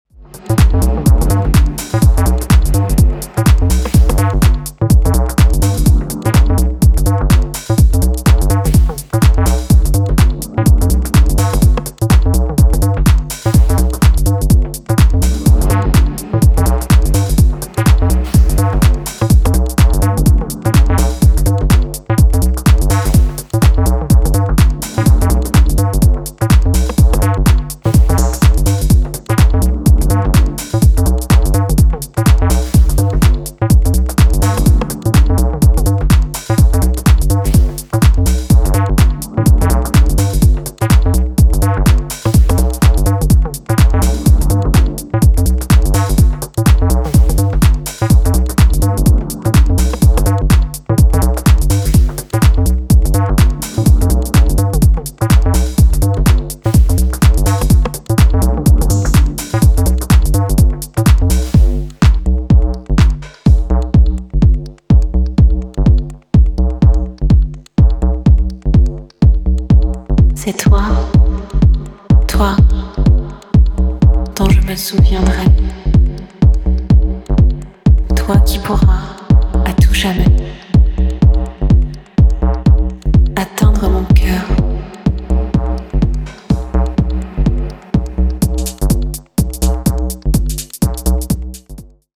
艶やかなシンセベースの響きとアブストラクトなウワモノが掛け合う
今回は全体的にダークでトリッピーなムードが際立っており、深い時間に良質な溜めを産んでくれるでしょう。